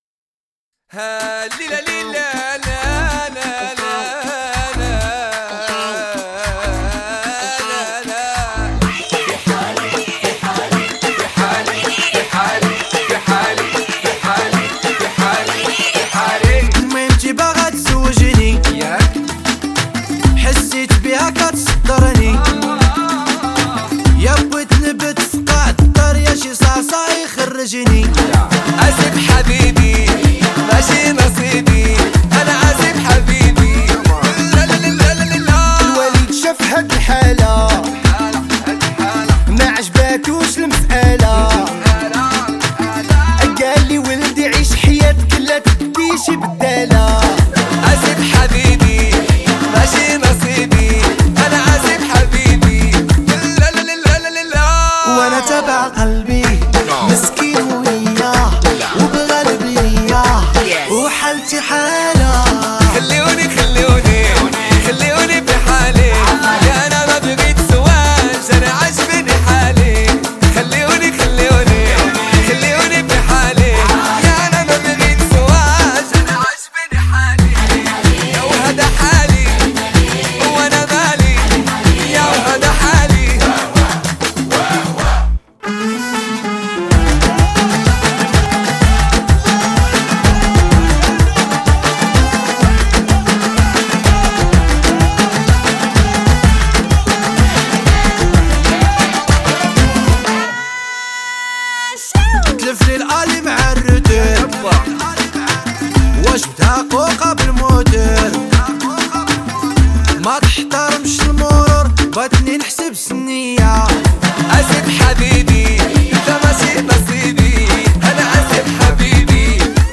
آهنگ عربی